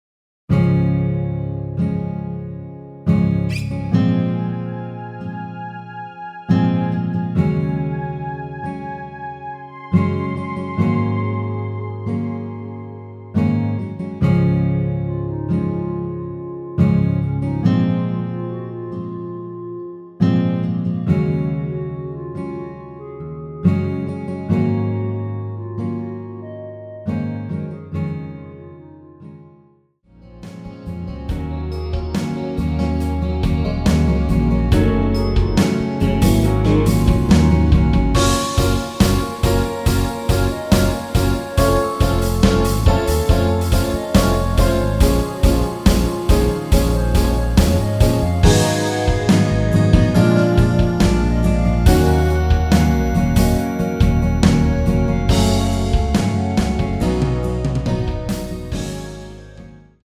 (-2) 내린 멜로디 포함된 MR 입니다.
Db
앞부분30초, 뒷부분30초씩 편집해서 올려 드리고 있습니다.
중간에 음이 끈어지고 다시 나오는 이유는